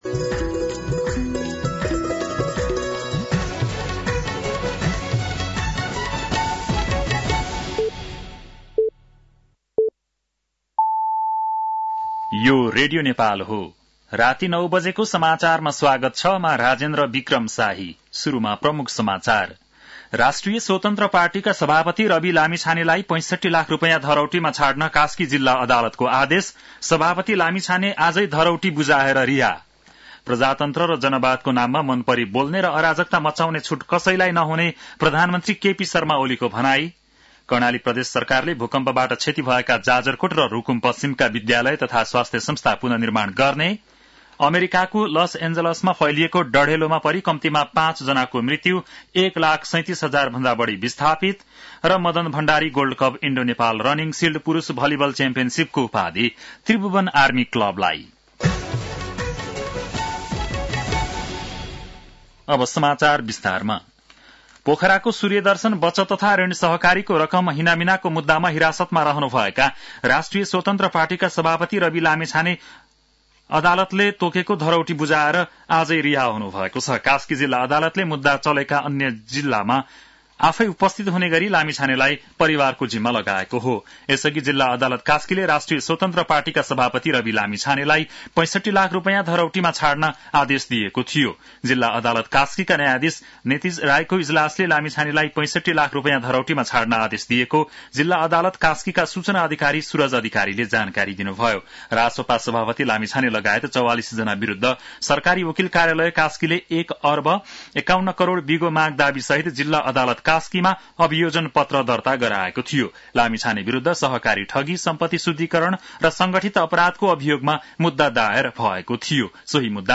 बेलुकी ९ बजेको नेपाली समाचार : २६ पुष , २०८१